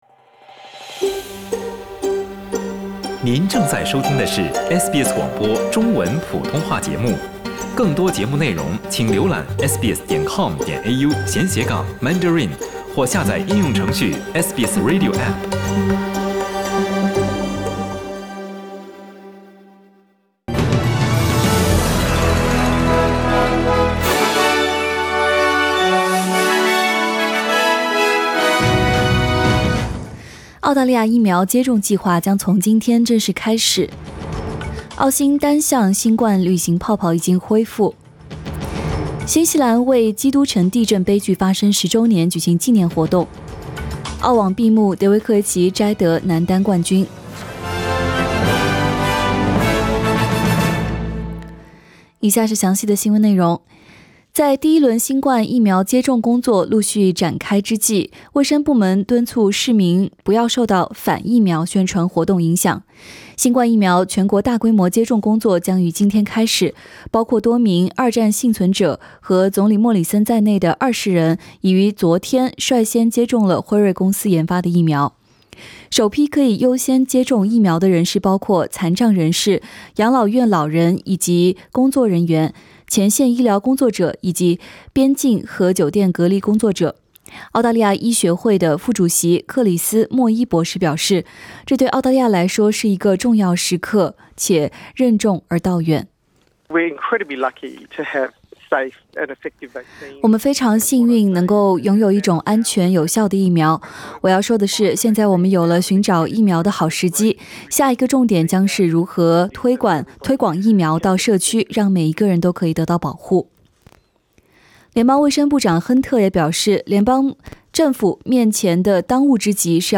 SBS Mandarin morning news Source: Getty Images